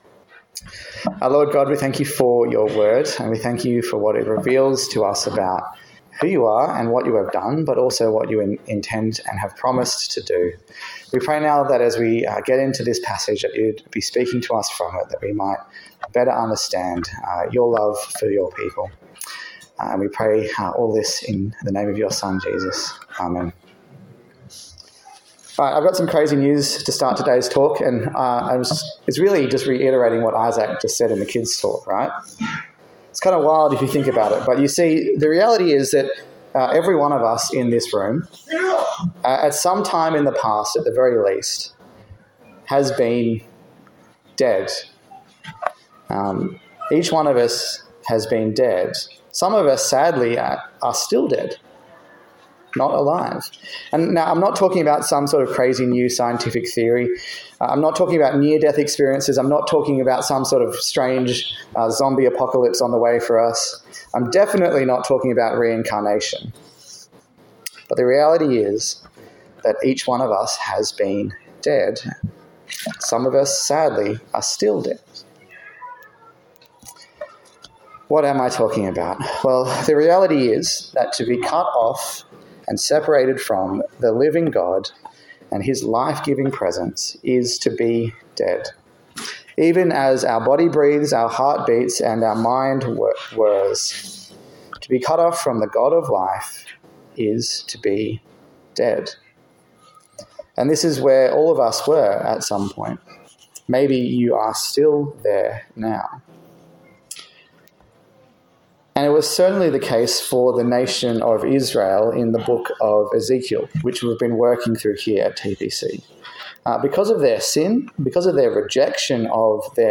Service Type: Morning Service